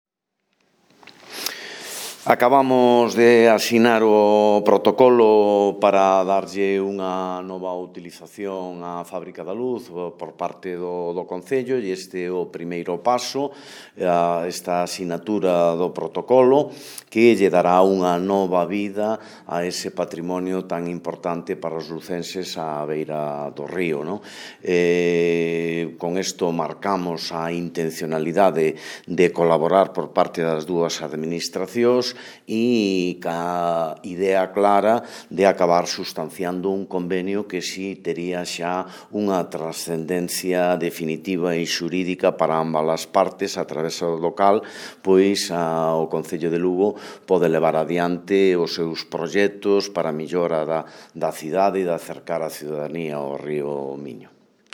• O presidente da CHMS, José Antonio Quiroga, sobre a colaboración coa Confederación Hidrográfica Miño-Sil |